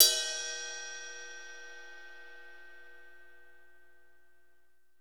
Index of /90_sSampleCDs/Northstar - Drumscapes Roland/CYM_Cymbals 2/CYM_F_T Cyms x